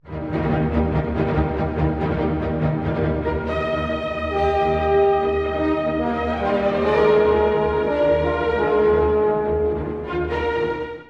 リズミックな伴奏によって、踊るような名人芸が奏でられます。
まるで祭りのような明るさですね！